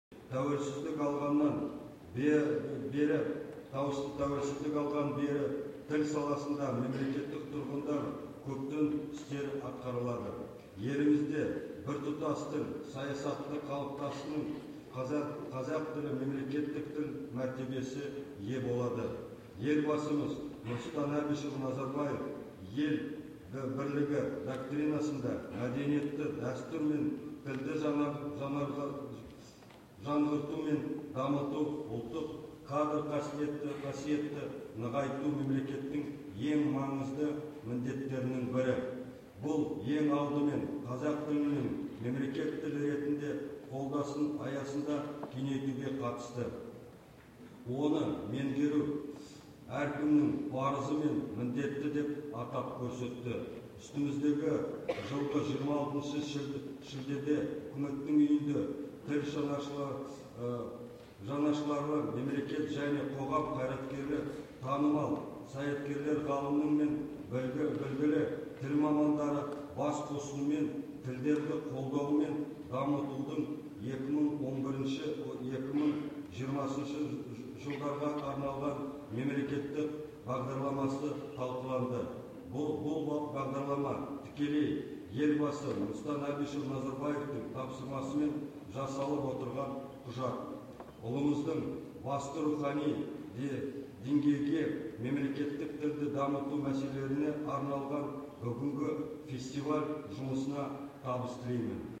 Солтүстік Қазақстан облысының әкімі Серік Біләлов Тілдер фестивалінің ашылу салтанатында сөз сөйледі. Он сөйлемнен тұратын тексті оқығанда жиырма шақты қате жіберді.
Құдды бір 1-ші сыныптың баласы сияқты ежіктеді ғой.